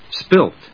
/spílt(米国英語)/